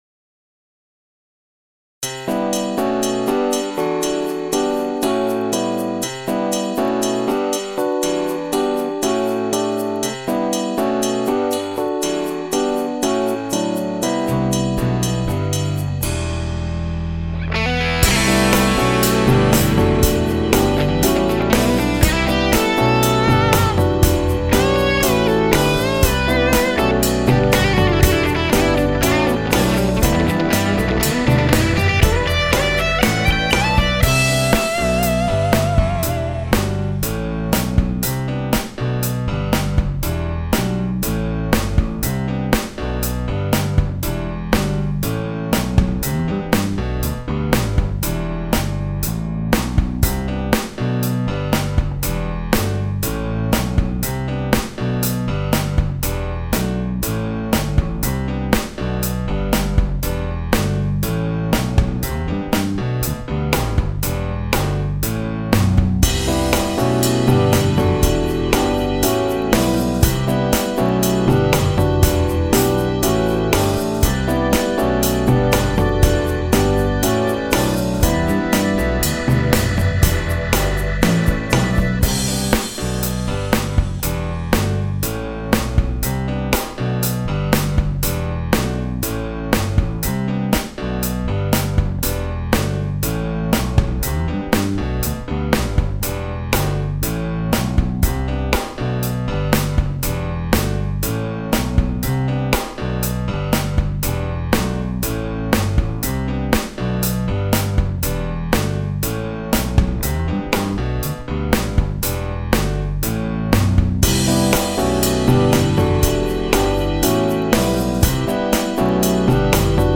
Unless otherwise illustrated, the MP3 tracks above are all recorded with the guitars ('98 Fender '57 RI USA Strat or '68 Gibson 335) straight into the Tweed Deluxe - with the exception of
Showbiz (Orange Squeezer).